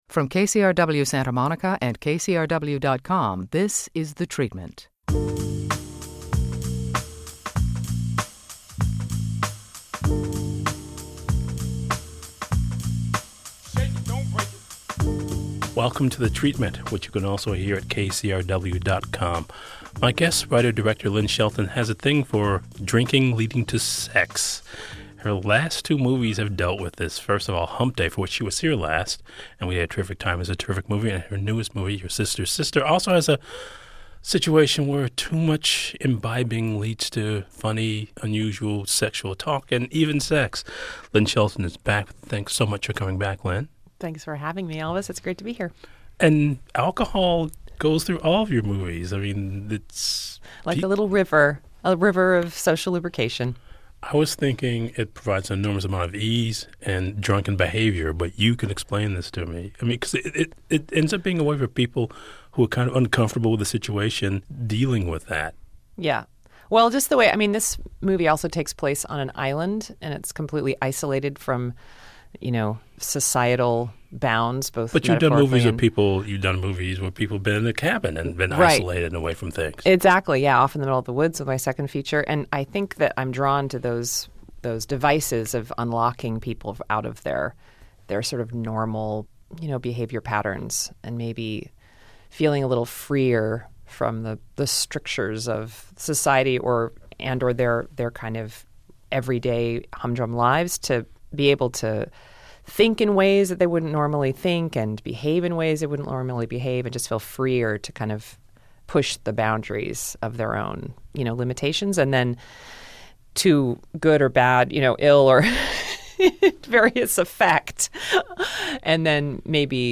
Elvis Mitchell talks to writer/director/actor Lynn Shelton about her latest film, 'Your Sister's Sister.'